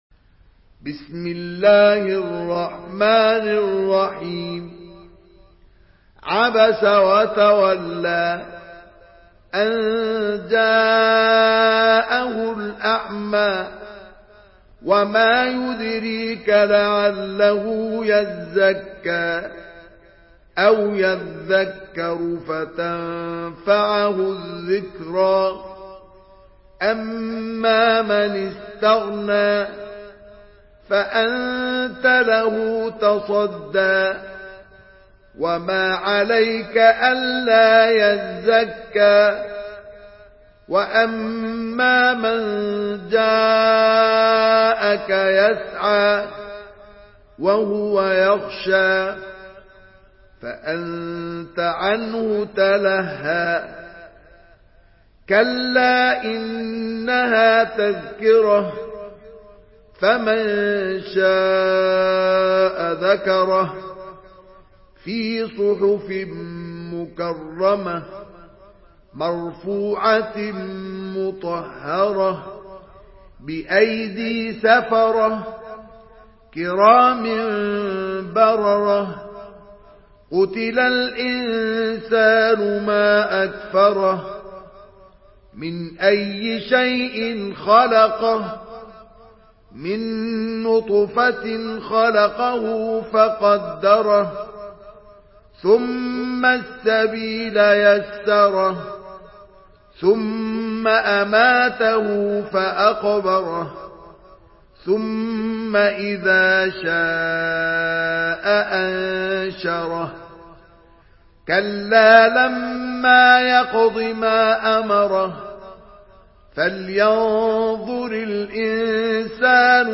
Surah Abasa MP3 in the Voice of Mustafa Ismail in Hafs Narration
Surah Abasa MP3 by Mustafa Ismail in Hafs An Asim narration.
Murattal